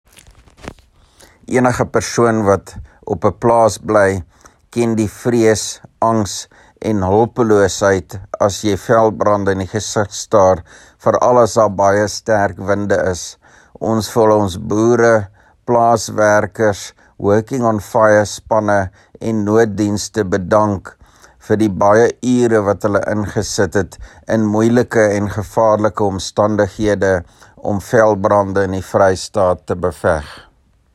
Afrikaans soundbites by Roy Jankielsohn MPL as well as images here and here